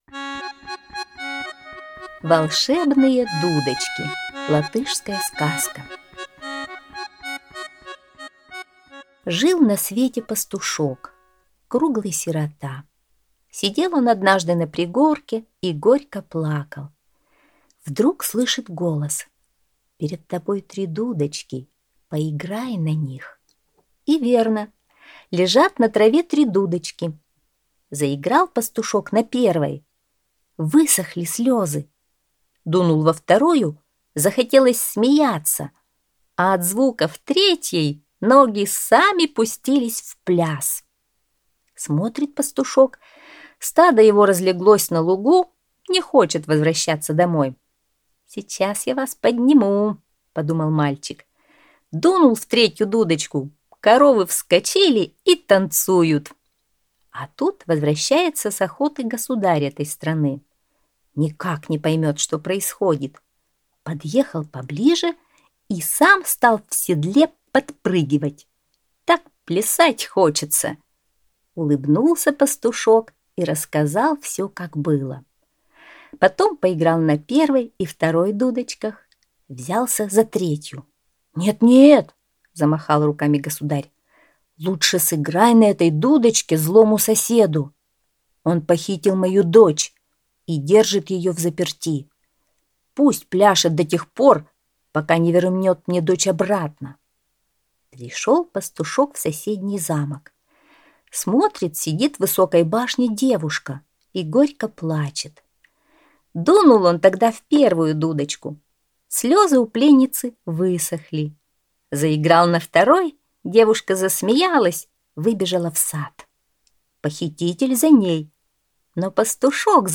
Латышская аудиосказка